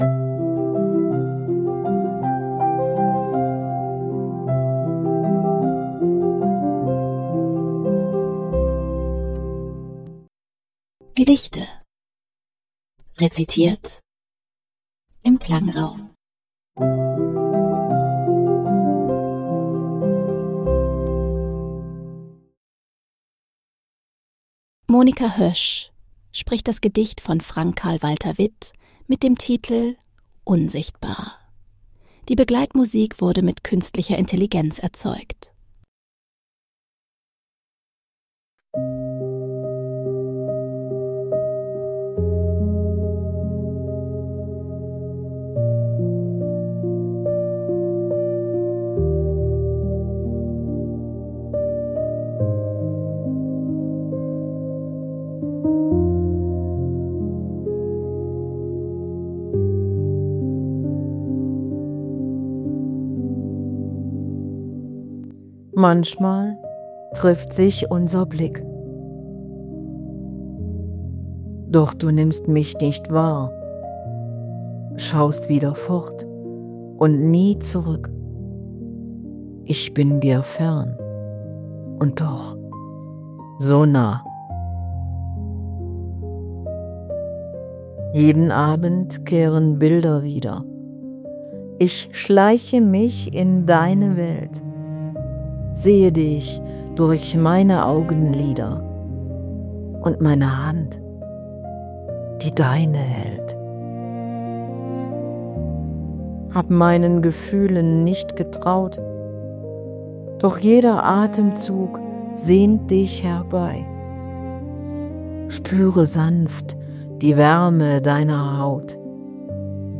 die Begleitmusik wurde mithilfe Künstlicher Intelligenz erzeugt.